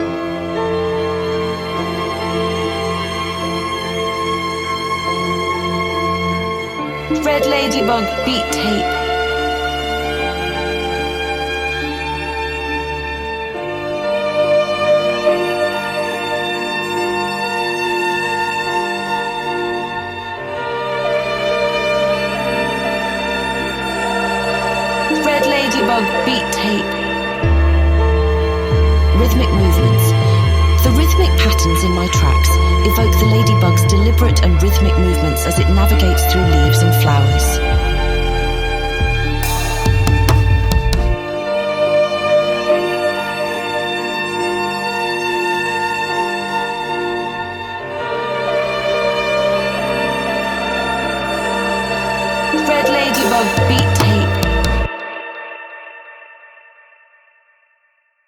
2. Boom Bap Instrumentals